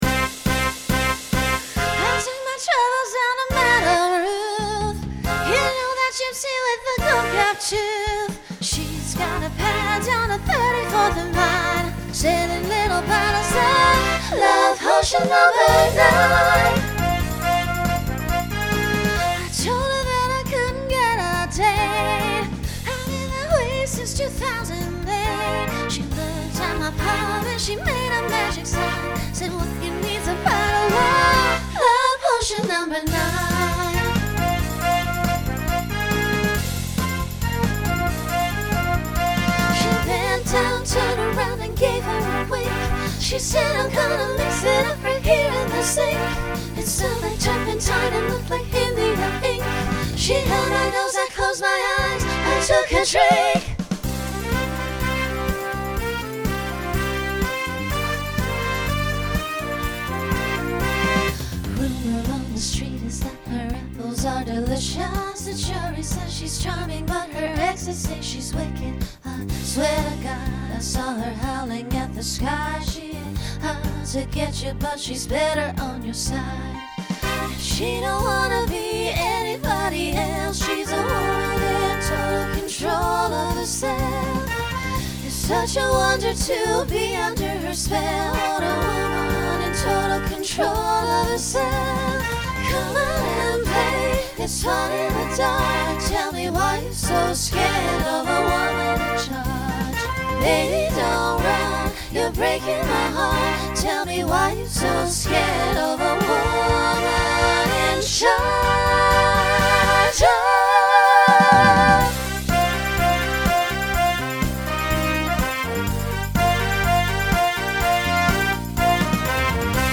Genre Rock
Transition Voicing SSA